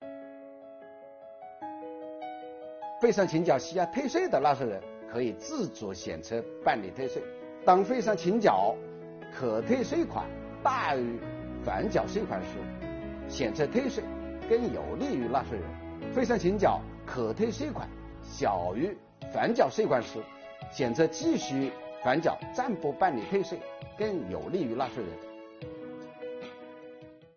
近日，国家税务总局推出新一批“税务讲堂”系列课程，为纳税人缴费人集中解读实施新的组合式税费支持政策。本期课程国家税务总局征管和科技发展司副司长付扬帆担任主讲人，对制造业中小微企业缓缴税费政策解读进行详细讲解，确保大家能够及时、便利地享受政策红利。